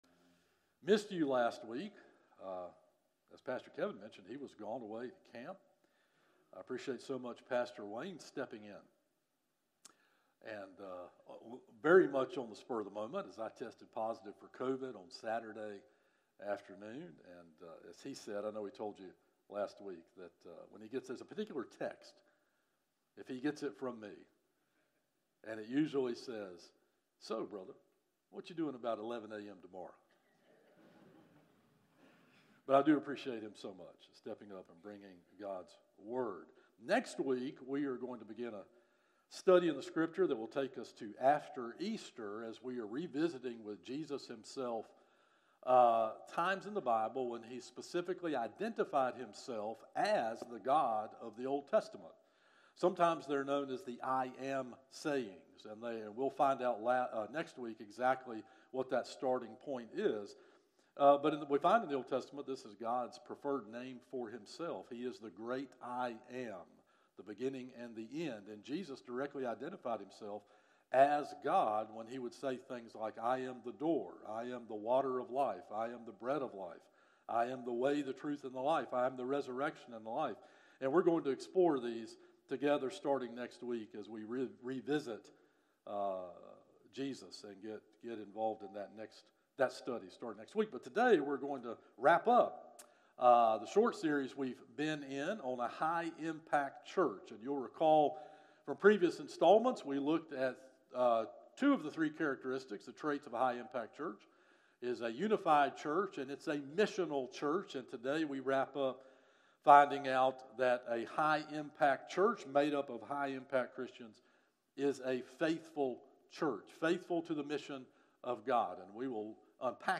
From Series: "Morning Worship - 11am"